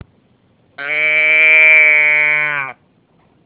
Adesso farò un esperimento multimediale: ecco la suoneria sul mio telefono
mooo.wav